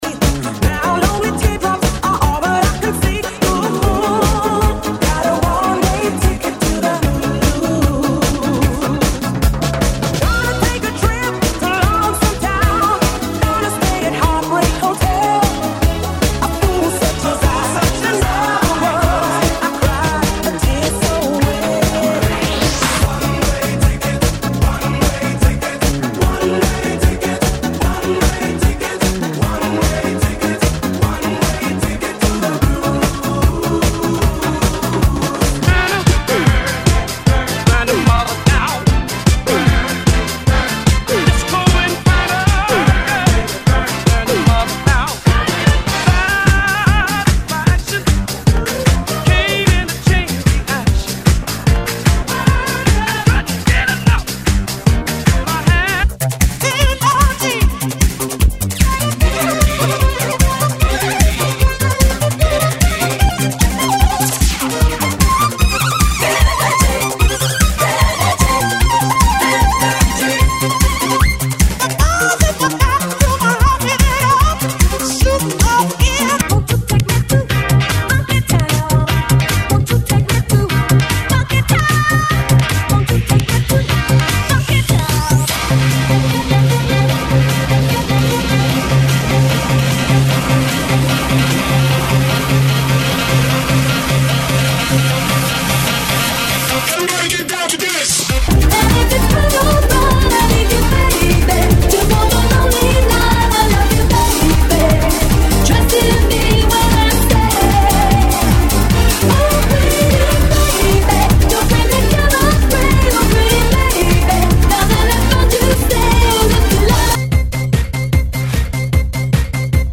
GENERO: MUSICA DISCO